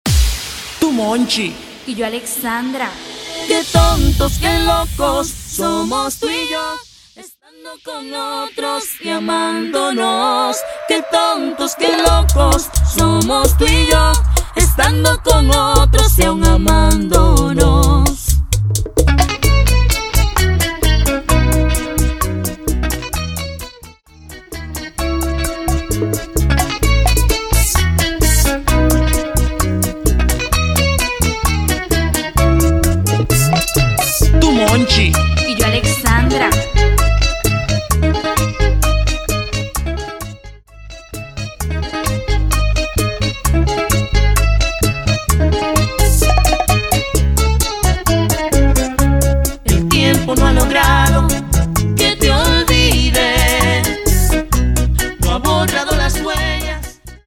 Specializing in Latin genres